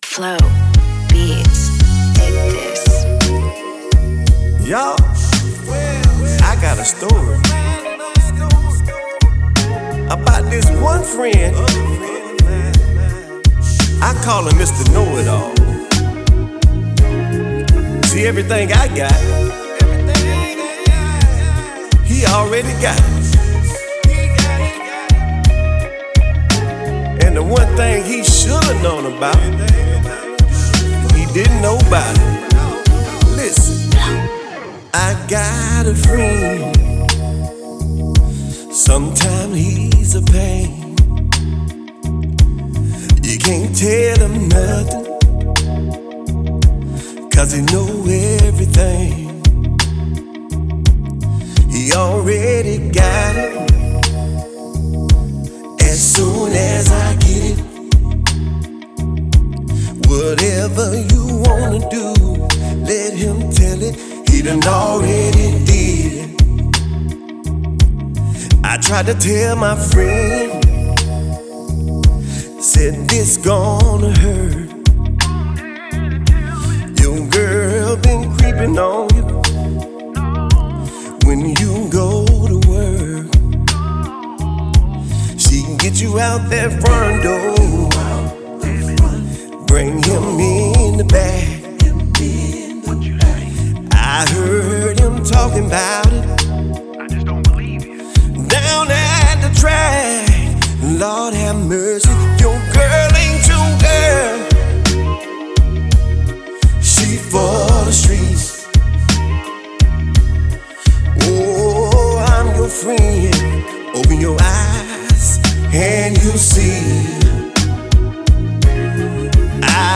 The result is a track that’s catchy and real.